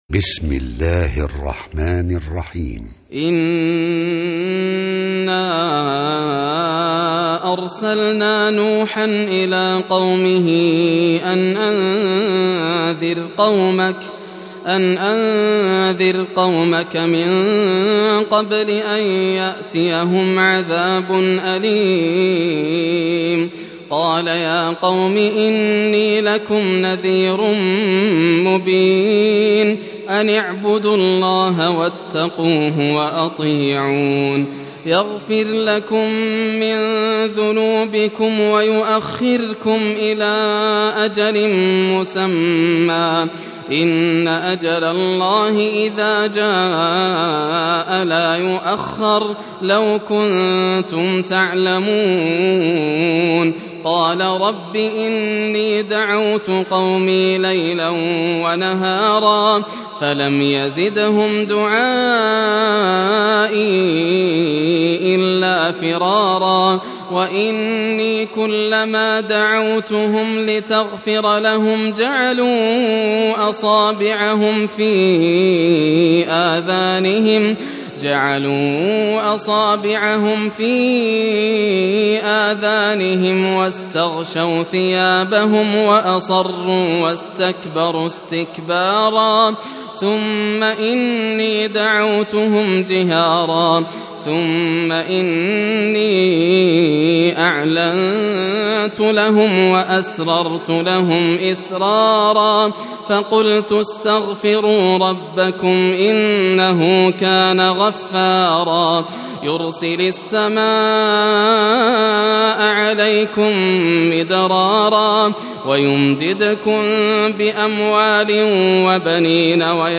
سورة نوح > المصحف المرتل للشيخ ياسر الدوسري > المصحف - تلاوات الحرمين